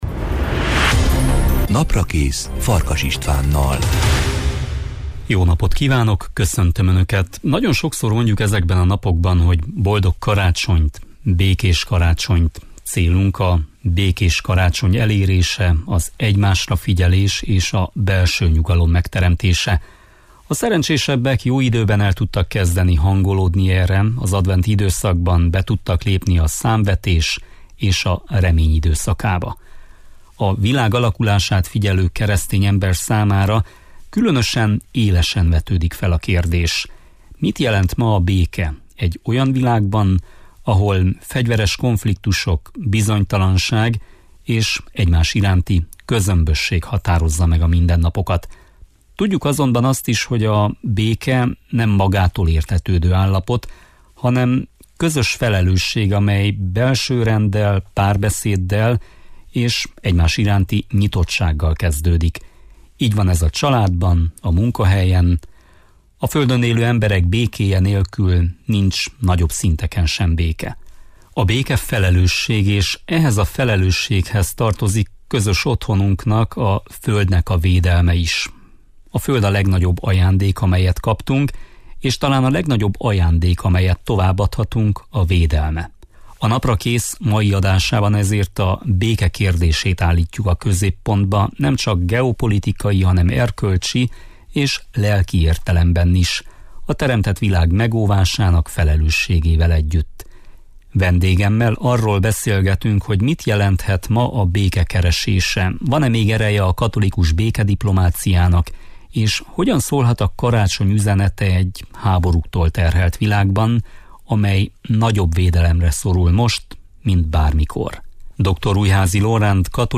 A Naprakész mai adásában ezért a béke kérdését állítjuk a középpontba – nemcsak geopolitikai, hanem erkölcsi és lelki értelemben is, a teremtett világ megóvásának felelősségével együtt. Vendégemmel arról beszélgetünk, mit jelenthet ma a béke keresése, van-e még ereje a katolikus békediplomáciának, és hogyan szólhat a karácsony üzenete egy háborúktól terhelt világban, amely nagyobb védelemre szorul most, mint bármikor.